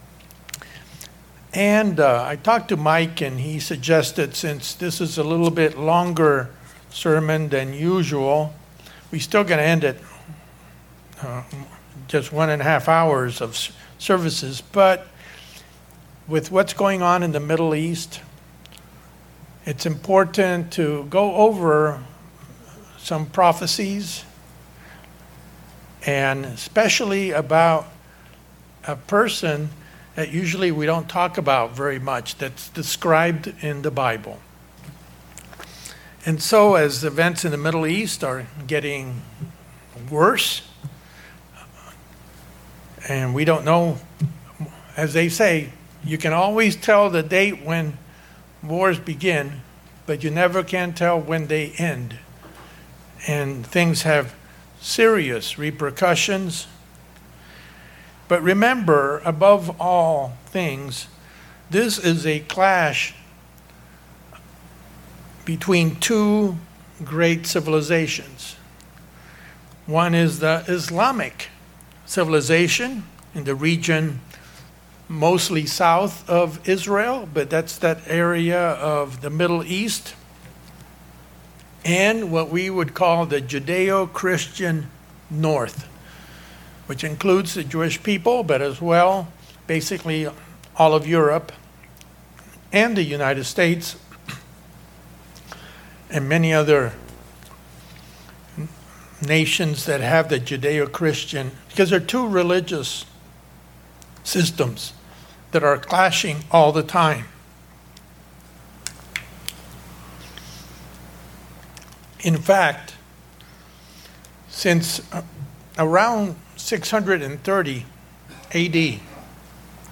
In this message, we look into the prophetic two beasts mentioned in Daniel and Revelation, the Antichrist, and Antiochus Epiphanes. What lessons do we learn from these entities?